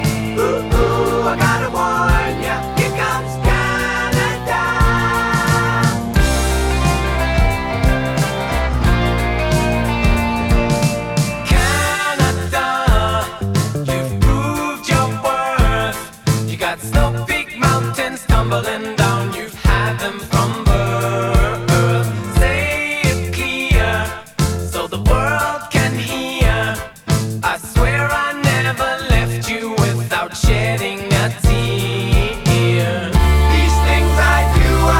1976-01-01 Жанр: Поп музыка Длительность